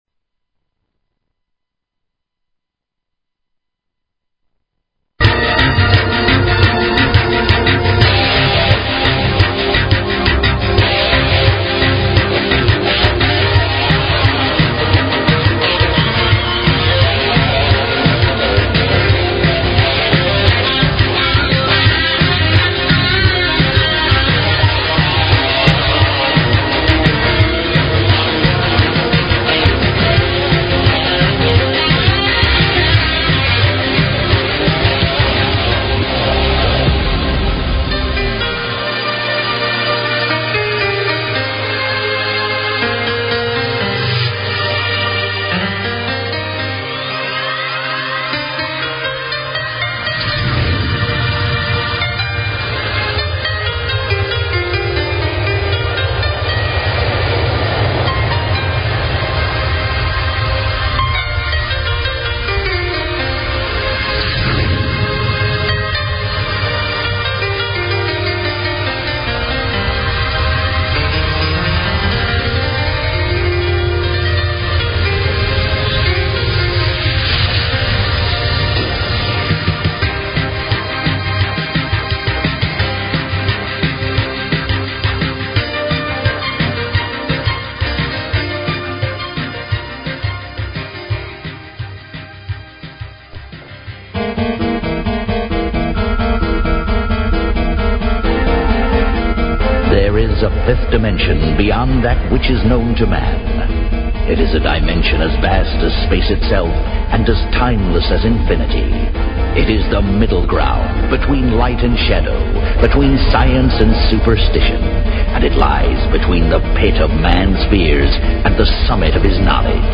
Talk Show Episode, Audio Podcast, Now_Thats_Weird and Courtesy of BBS Radio on , show guests , about , categorized as